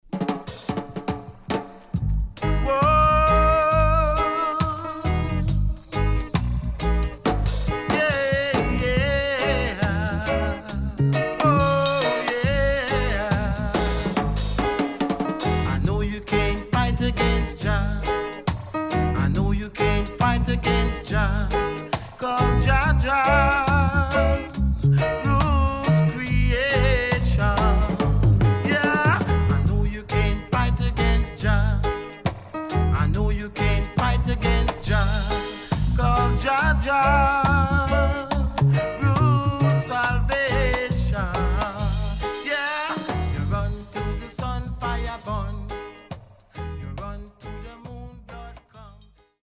HOME > REISSUE [REGGAE / ROOTS]
Killer & Deep Roots & Dubwise.W-Side Good